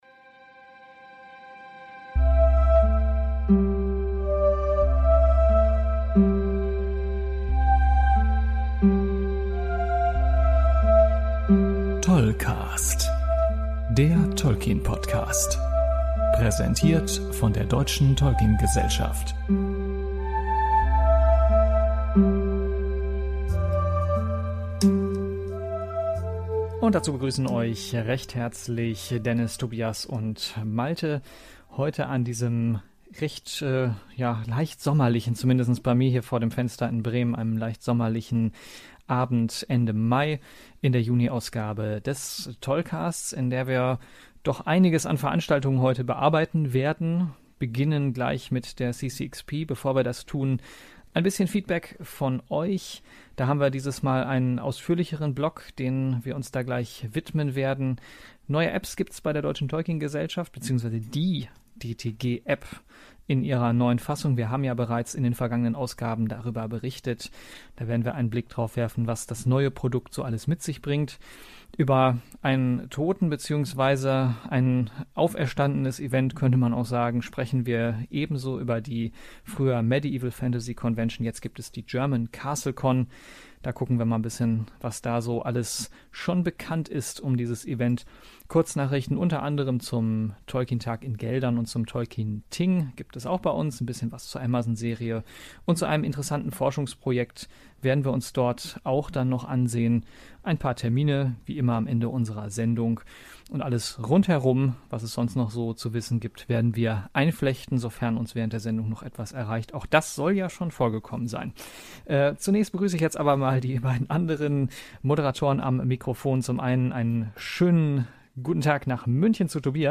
Gleich zu Anfang gibt es eine Überraschung, von der selbst zwei Drittel der Moderatoren nichts wussten! Anstehende eigene Veranstaltungen wie der Tolkien Tag am Linken Niederrhein oder das Tolkien Thing in Thüringen sind ebenso Thema wie externe Events wie etwa die CCXP oder die MFC.